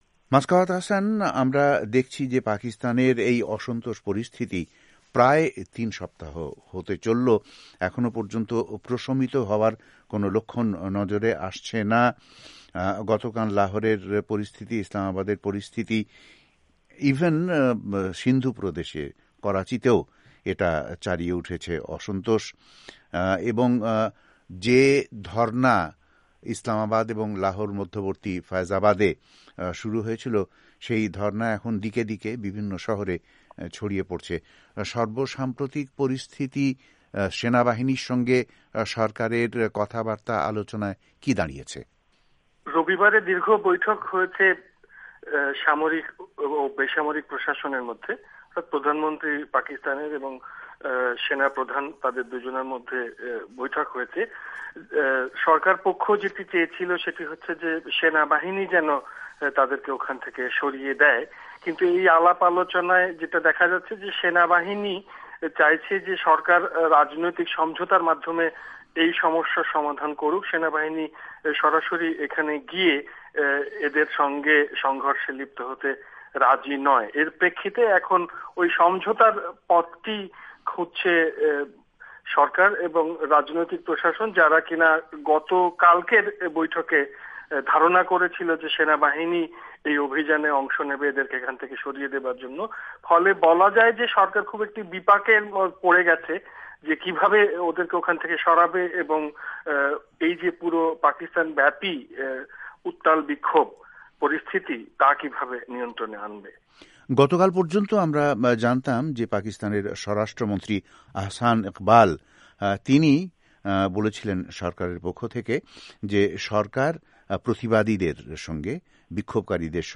পাকিস্তানে ক্ষমতাসীন সরকারের নির্বাচনী আইন সংষ্কার নিয়ে আলোচনা